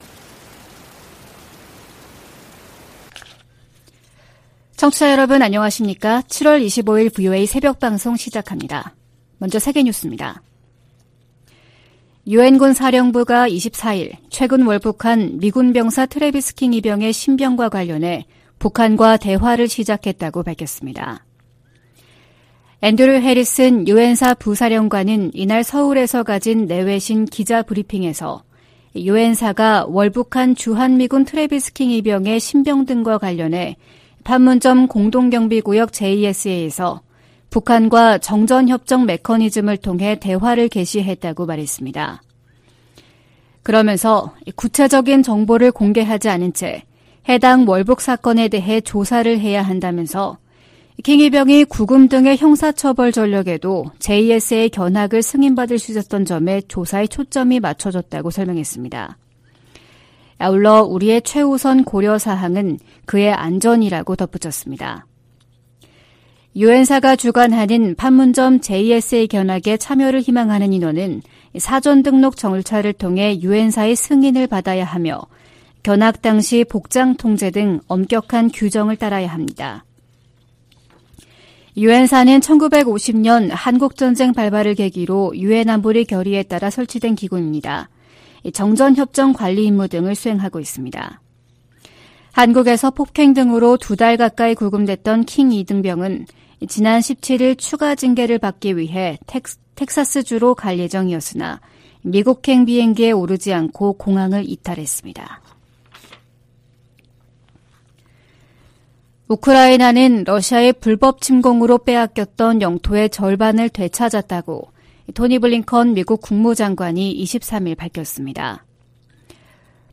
VOA 한국어 '출발 뉴스 쇼', 2023년 7월 25일 방송입니다. 북한은 지난 19일 동해상으로 단거리 탄도미사일(SRBM) 2발을 발사한데 이어 사흘 만에 다시 서해상으로 순항미사일 수 발을 발사했습니다. 미국은 전략핵잠수함의 부산 기항이 핵무기 사용 조건에 해당된다는 북한의 위협을 가볍게 여기지 않는다고 백악관이 강조했습니다. 유엔군사령부는 월북한 주한미군의 신병과 관련해 북한과의 대화를 시작했다고 공식 확인했습니다.